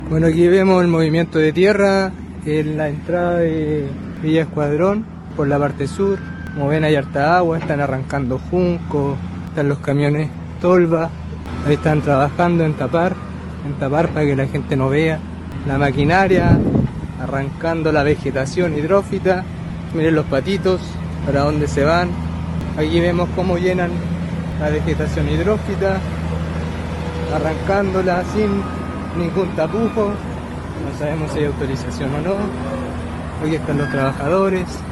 Fue un vecino que registró el momento donde maquinaria pesada realizaba movimientos de tierra y rellenos en el humedal Escuadrón de Coronel.
El denunciante incluso encaró a los trabajadores que realizaban faenas al momento de la grabación y a su vez describió cómo dichas obras afectan la vegetación hidrófita de esa zona.